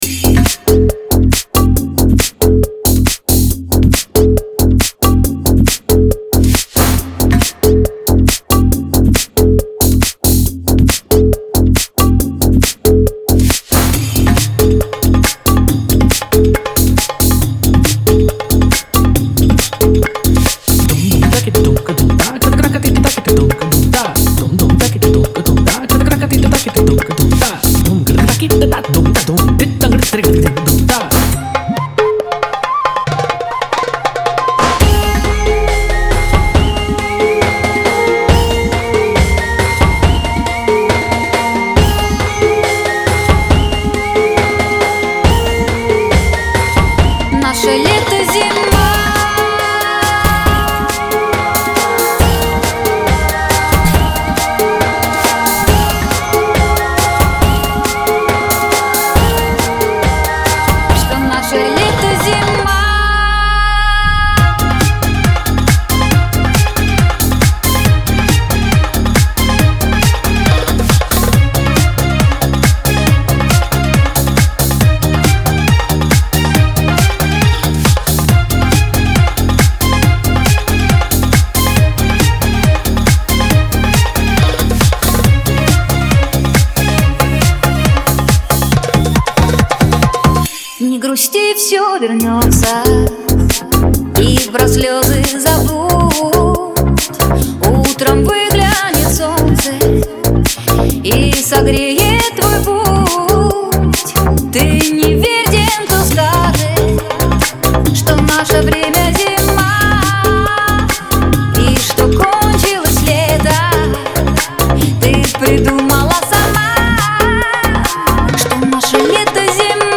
Лирика
pop , диско
эстрада